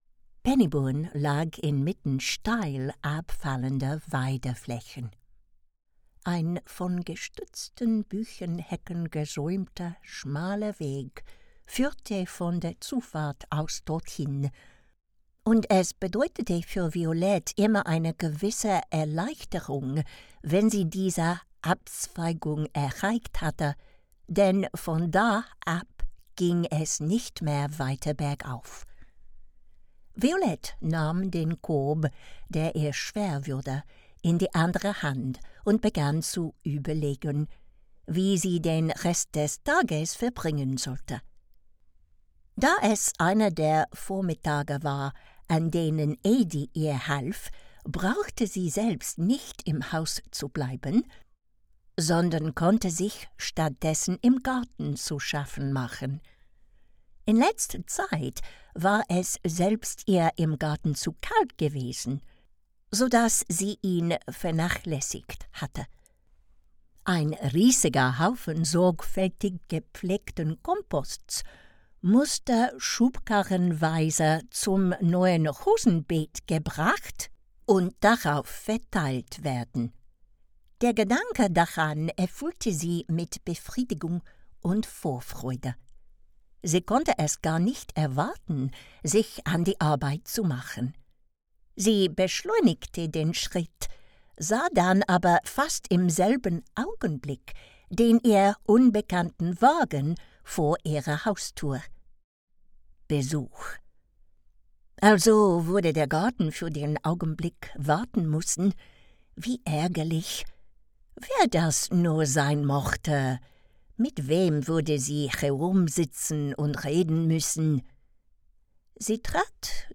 Extract from September by Rosamunde Pilcher
Adult German